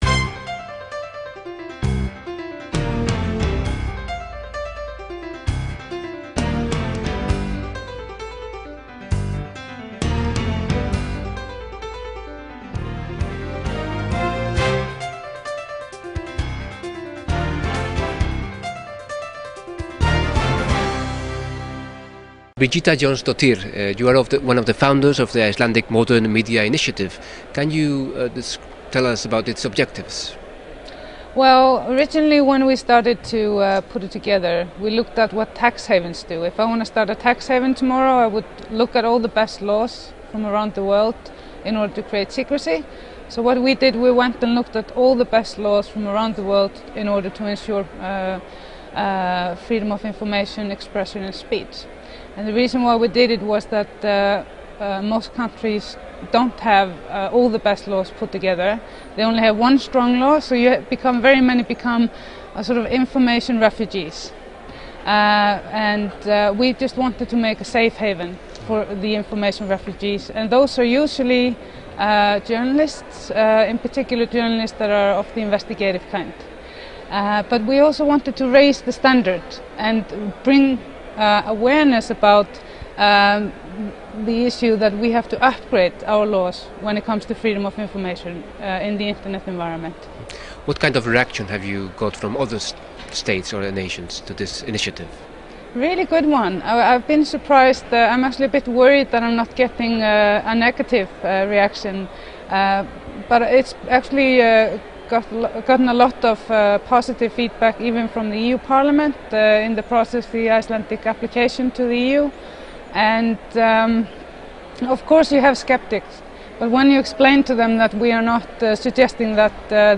Internet Freedom: Interview with Birgitta Jónsdóttir
In this interview, Icelandic parliamentarian Birgitta Jónsdóttir tells the Council of Europe about the background of the Icelandic Modern Media Initiative (IMMI), and the importance of freedom of information.